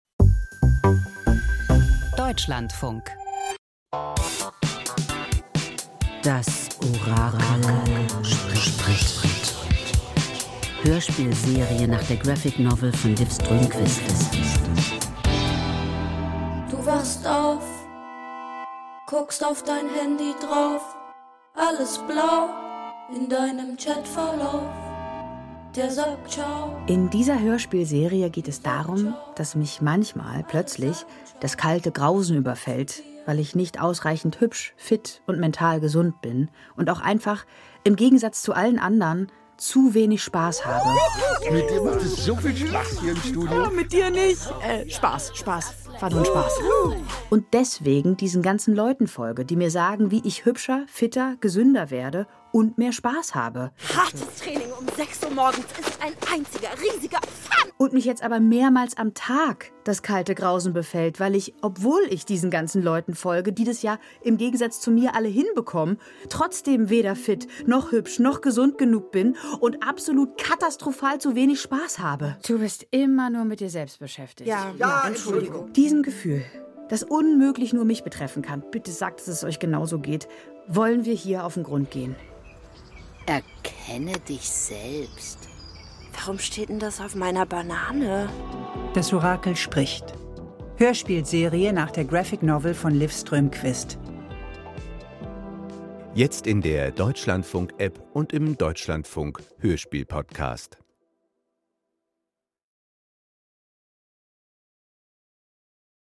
Hörspielserie nach Liv Strömquist